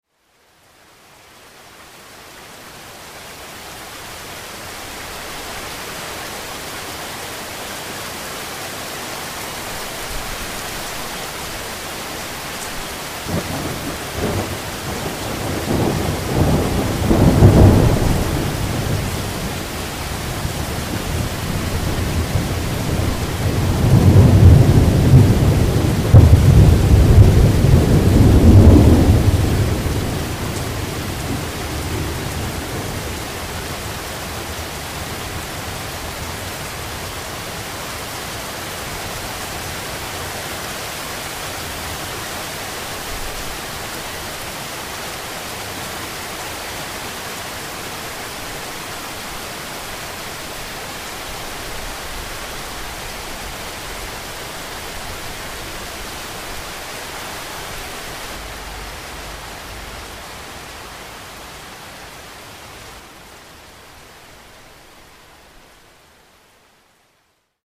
Pioggia con tuono
Pioggia che cambia di intensità
Pioggia con tuono.mp3